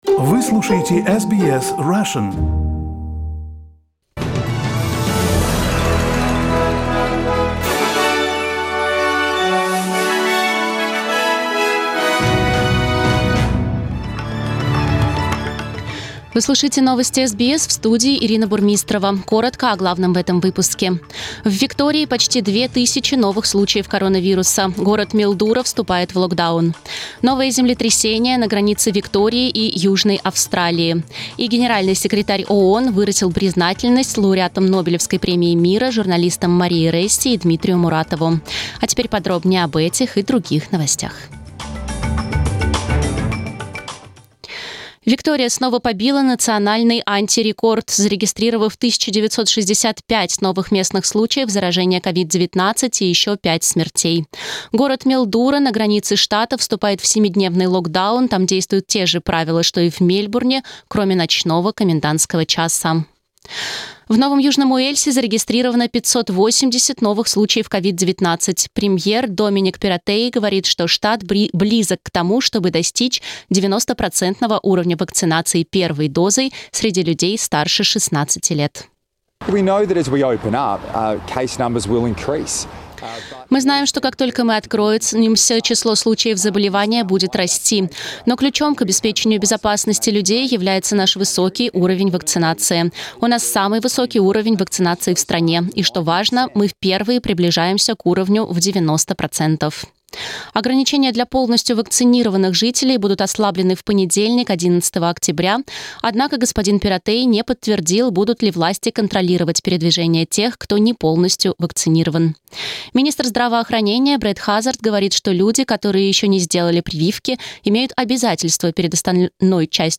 Listen to the top news headlines from SBS Russian.